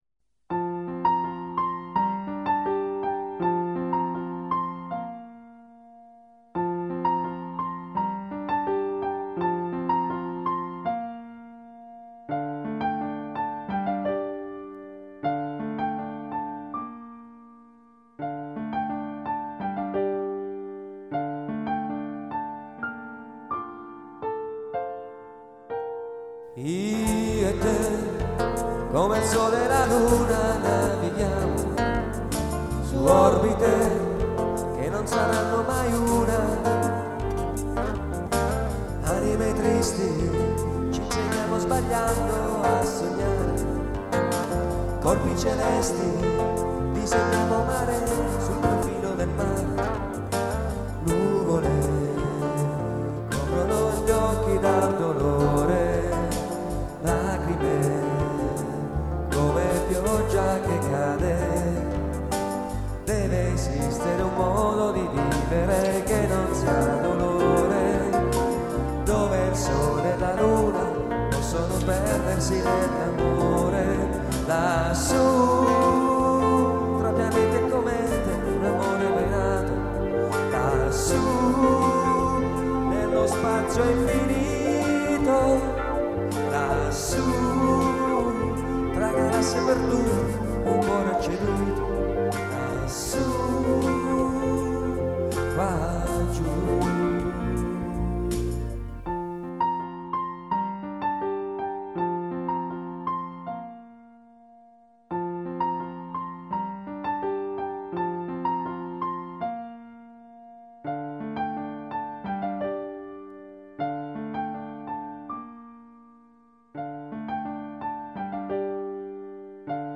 Voce e cori
Piano
Chitarra acustica e elettrica
Basso